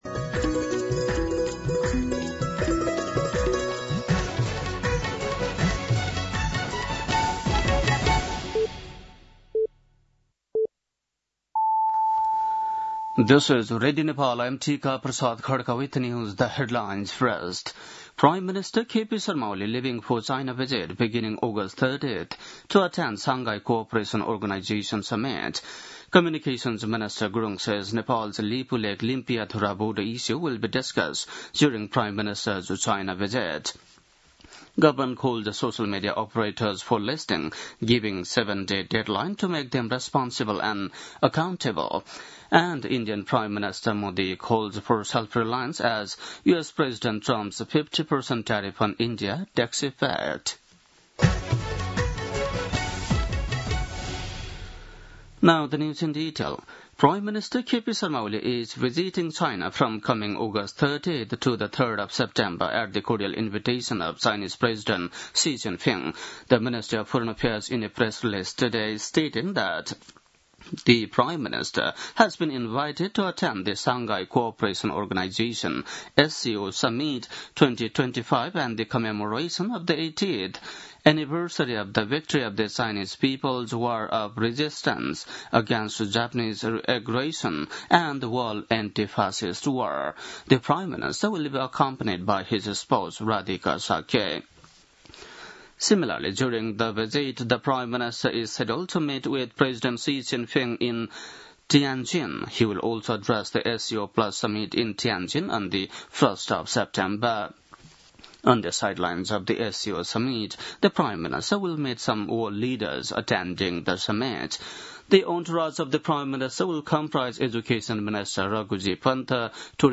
बेलुकी ८ बजेको अङ्ग्रेजी समाचार : ११ भदौ , २०८२